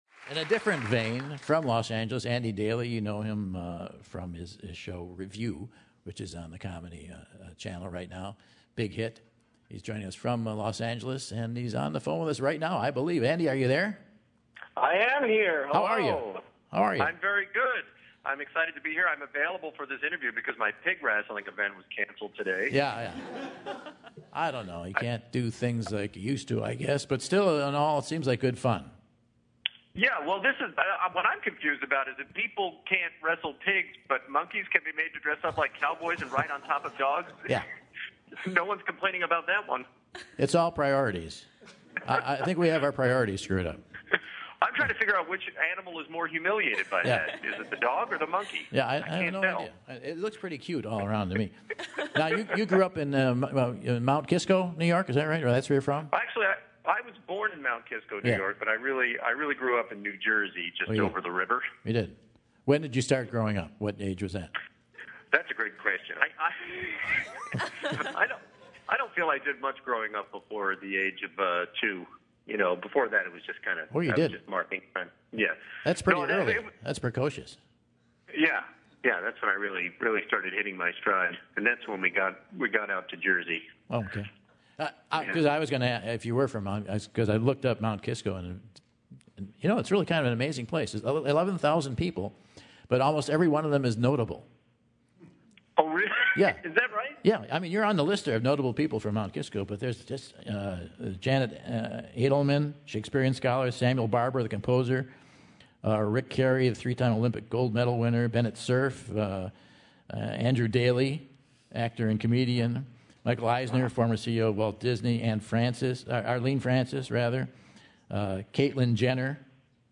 Michael welcomes Andrew Daly, star of Comedy Central's Review, to the show! They chat about the hit comedy series, his thoughts on taking a dramatic role and pig rasslin'!